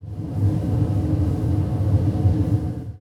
default_furnace_active.ogg